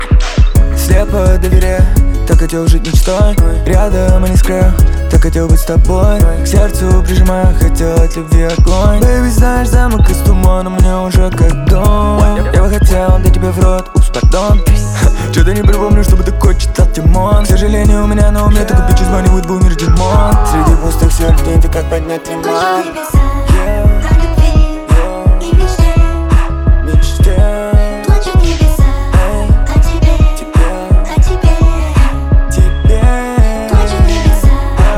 Жанр: Русские
# Хип-хоп